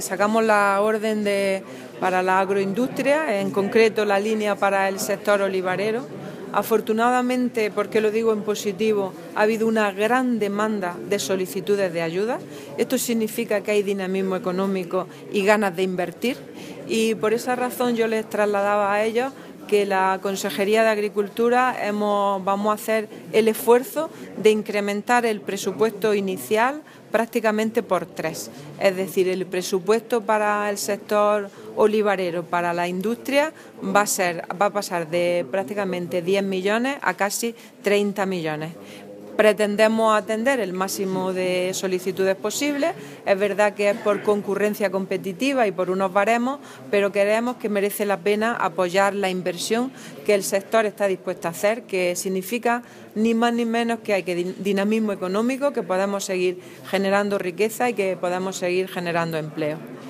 Declaraciones consejera sobre la cooperativa